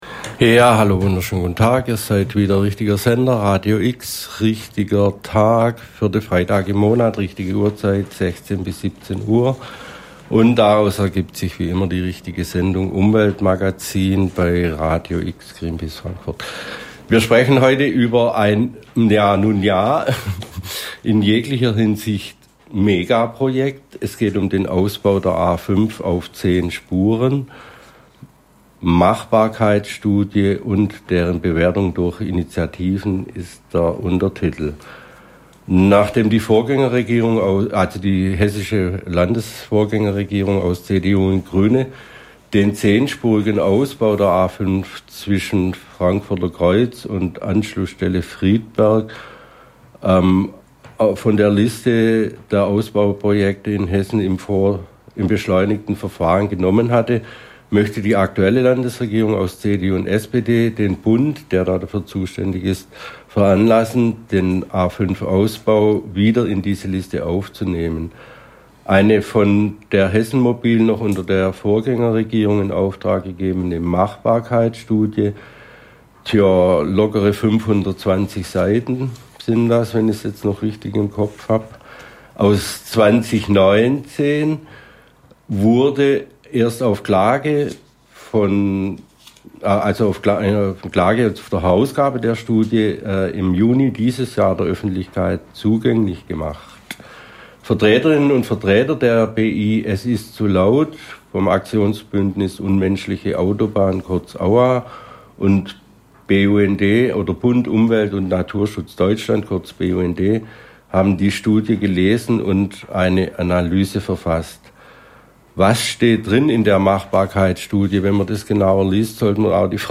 Interview im Radio X Umweltmagazin
BI "Es ist zu laut!", BUND und AUA im Interview zur Analyse der A5 Machbarkeitsstudie